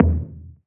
DDWV POP TOM 1.wav